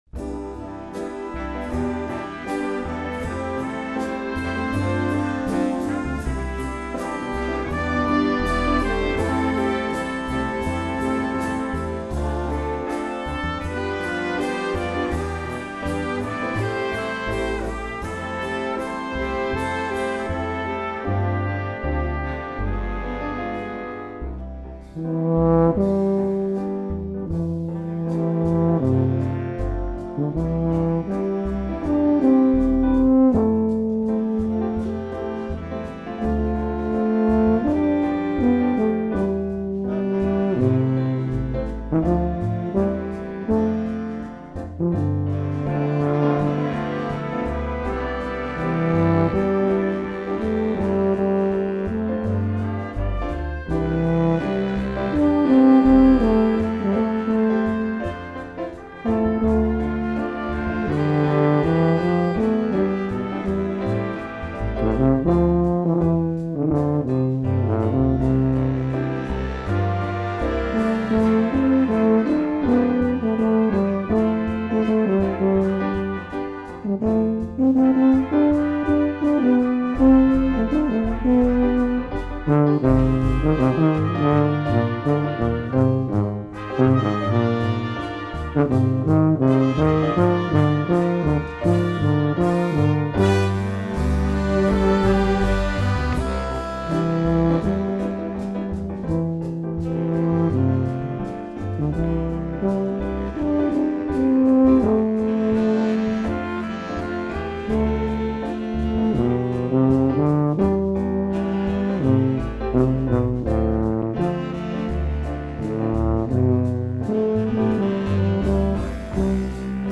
bass trombone and tuba section player
tuba solo: